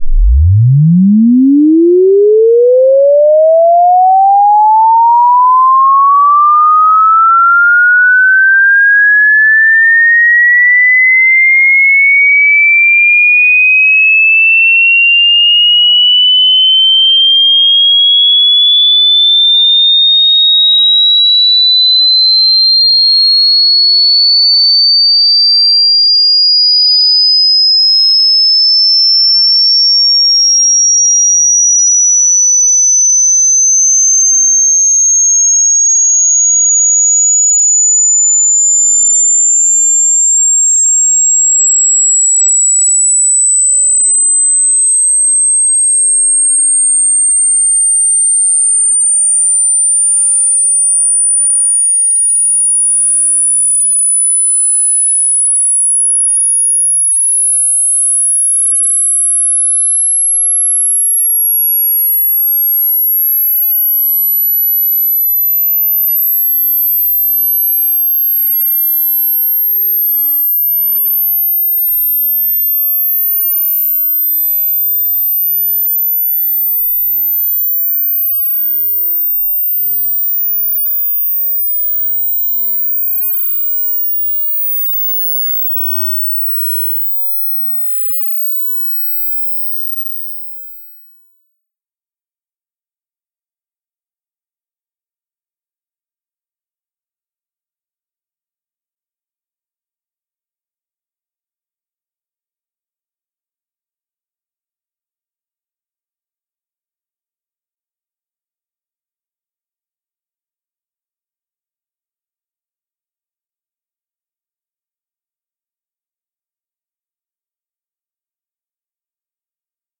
正弦扫频" 0赫兹至22500k赫兹，2分钟内完成
描述：在Audacity产生的正弦波扫描从0赫兹到22.5千赫兹。